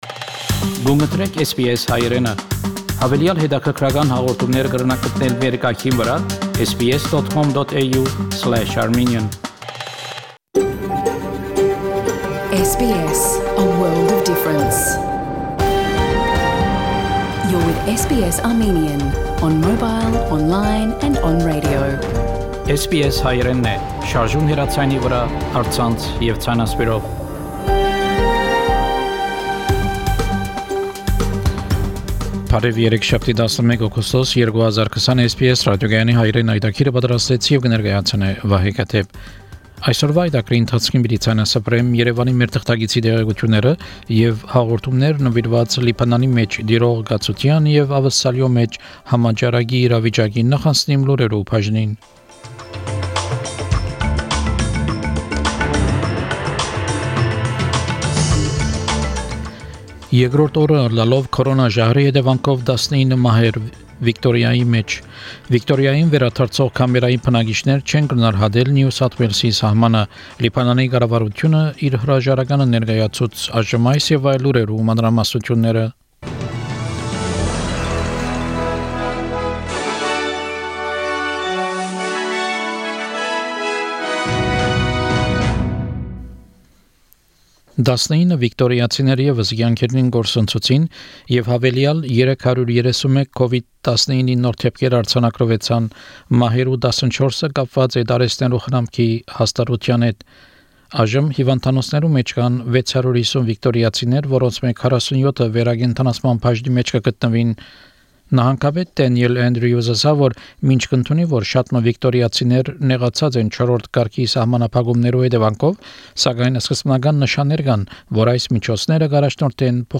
SBS Armenian news bulletin – 11 August 2020
SBS Armenian news bulletin from 11 August 2020 program.